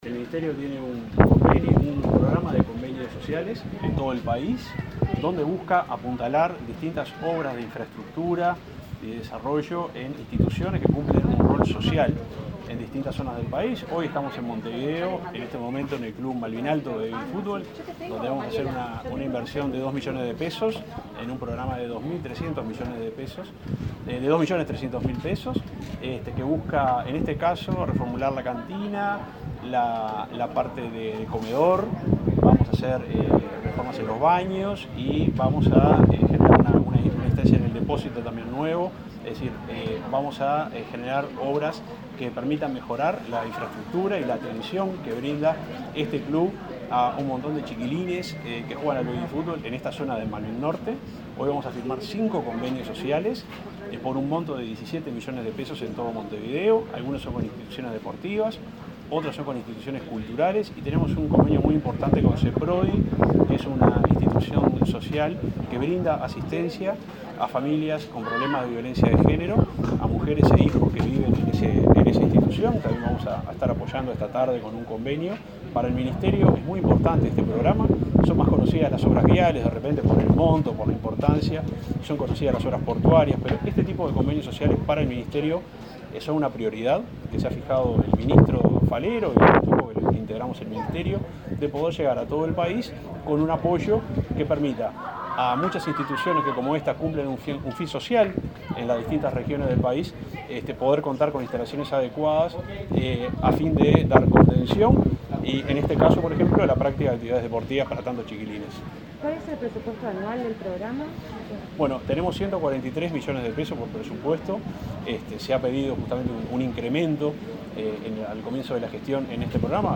Declaraciones a la prensa del subsecretario de Transporte, Juan José Olaizola